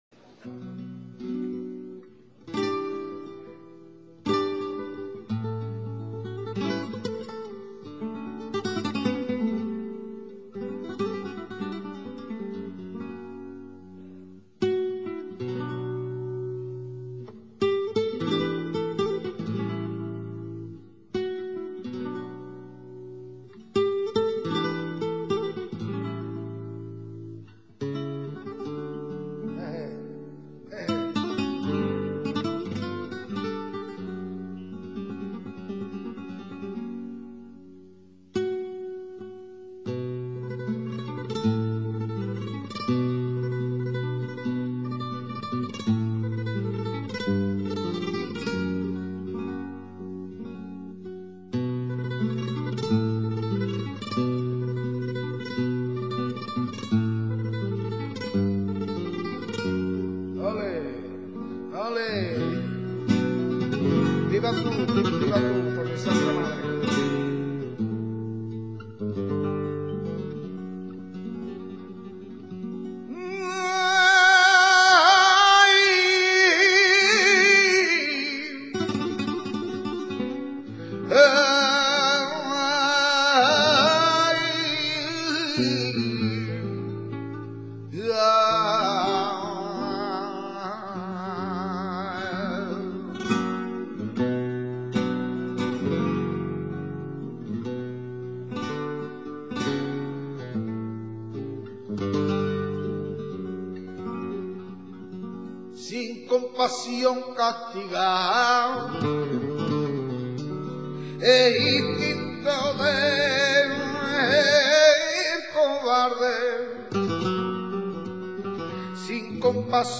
Sonidos y Palos del Flamenco
Cante con copla de cuatro versos octos�labos que al cantarse se convierten generalmente en seis por repetici�n de uno de ellos y el a�adido de otro ajeno a la copla. De entonaci�n pausada, mel�dica, sentimental y majestuosa.
petenera.mp3